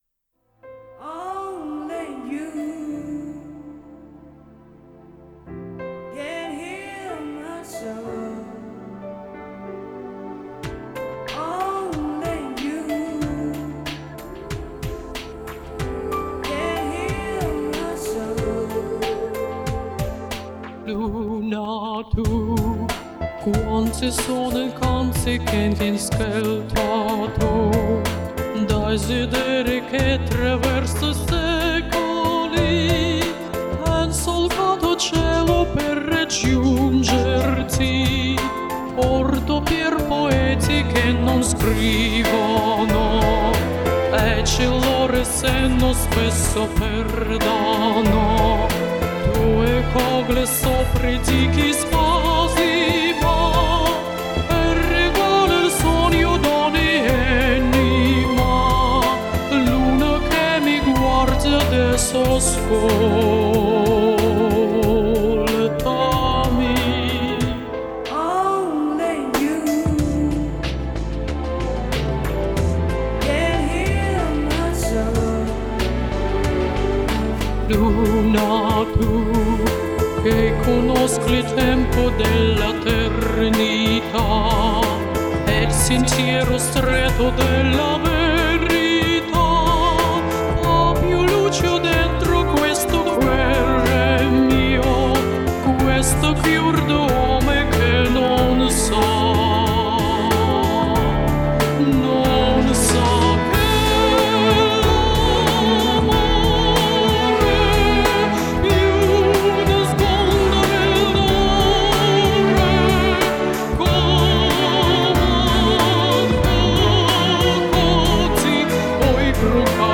Трудно сравнивать женское и мужское исполнение